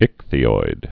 (ĭkthē-oid)